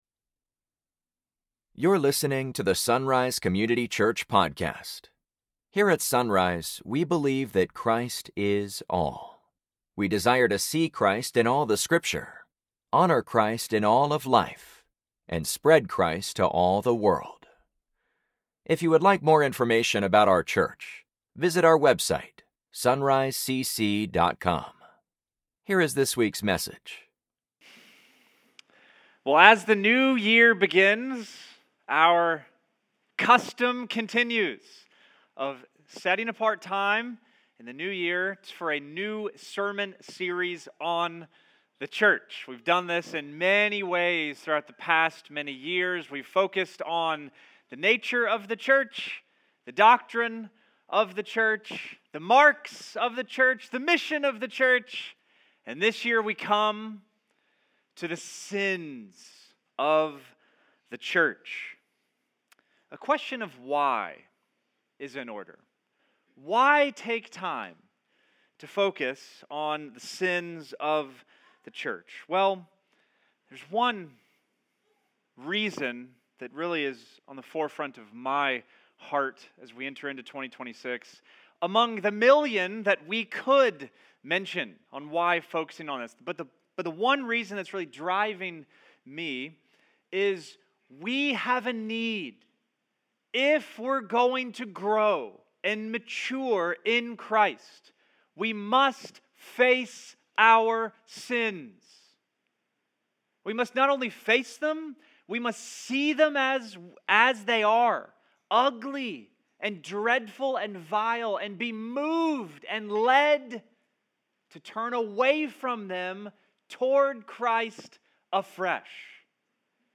Sunday Mornings | SonRise Community Church
As the New Year begins we now enter into a new sermon series on the church.